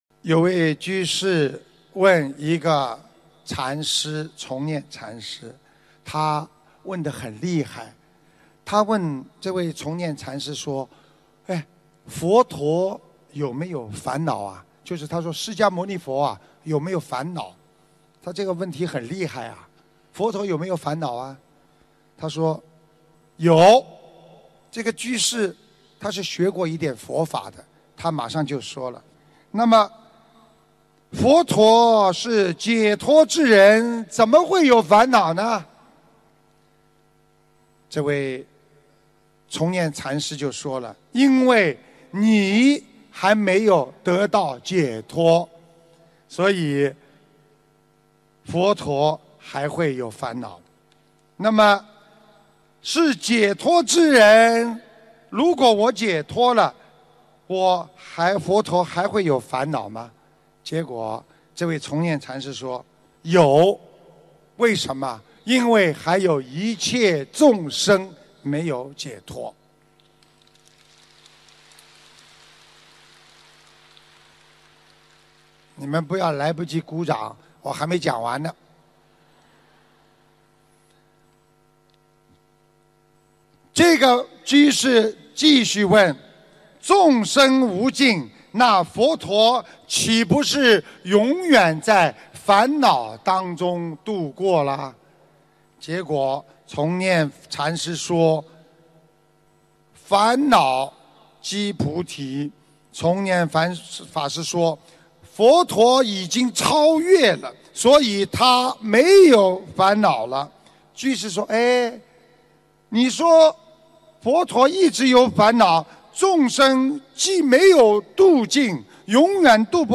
法会开示